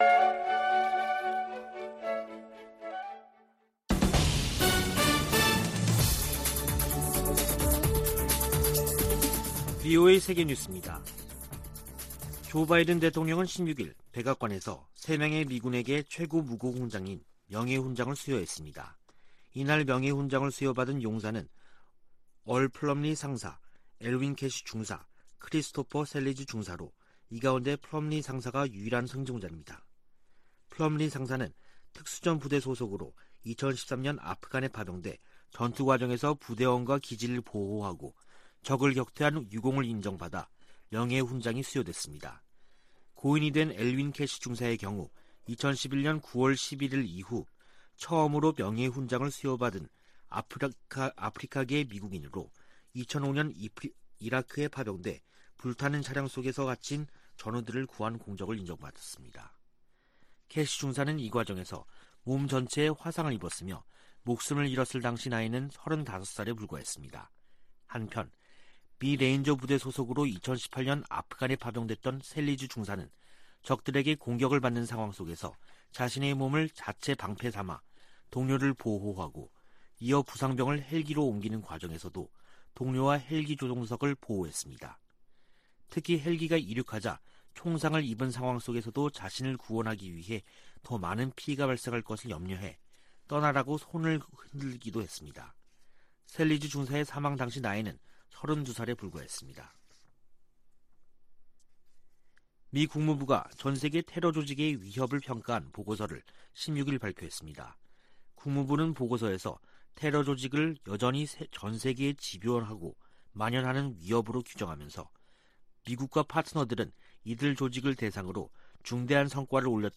VOA 한국어 간판 뉴스 프로그램 '뉴스 투데이', 2021년 12월 17일 3부 방송입니다. 미 국무부는 북한이 반복적으로 국제 테러 행위를 지원하고 있다고 보고서에서 지적했습니다. 미국은 북한과 대화와 외교를 통한 한반도의 항구적인 평화를 위해 노력하고 있다고 국무부 부차관보가 밝혔습니다. 미 국무부가 미한 동맹의 중요성을 거듭 확인하면서 중국과 관여하는 문제에도 협력을 강조했습니다.